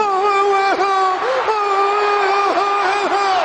Play, download and share Boohoo original sound button!!!!
the-rock-crying.mp3